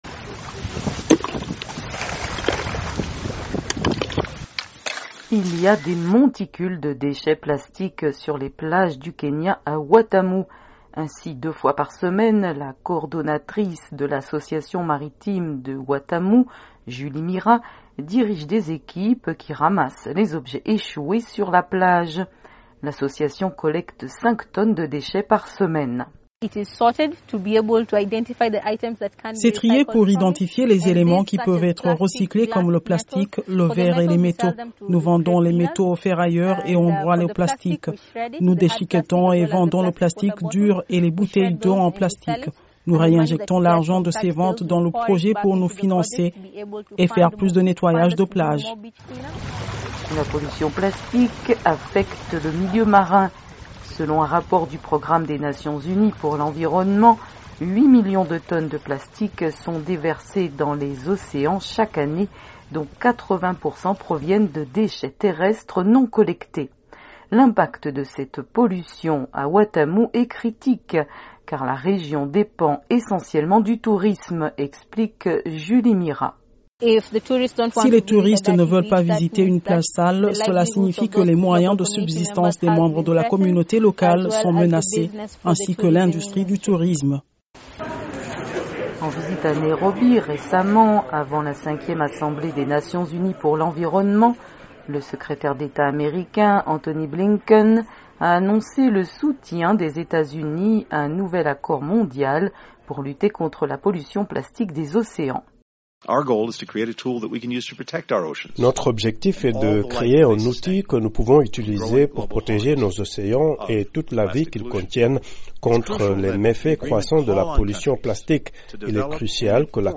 Au Kenya, les militants écologistes accueillent favorablement le soutien des États-Unis à un accord mondial pour lutter contre la pollution plastique dans les océans. Sans attendre la mise en oeuvre de mesures concrètes au plan national et international, une initiative communautaire agit en ce sens en collectant et recyclant le plastique échoué sur les plages du Kenya, où la plupart des habitants dépendent du tourisme pour gagner leur vie. Un reportage